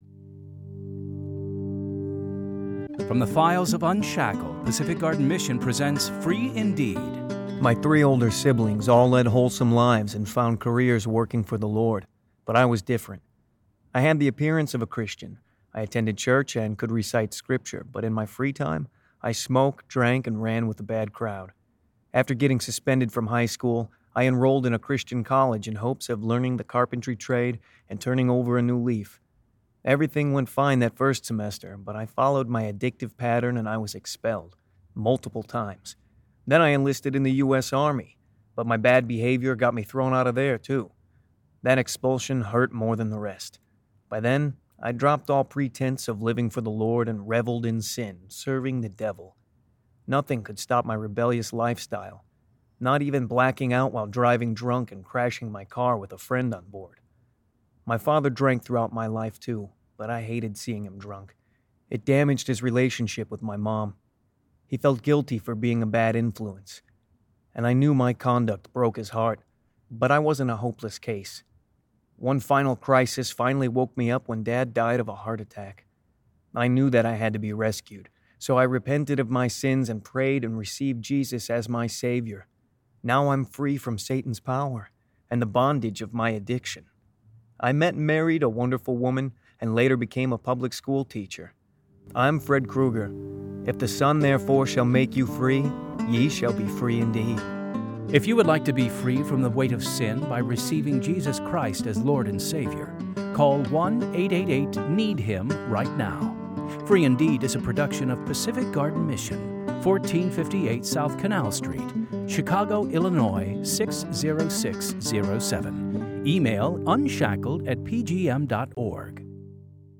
Two Minute Testimonies of True Life Stories
Free Indeed! stories are created from the files of UNSHACKLED! Radio Dramas, and produced by Pacific Garden Mission.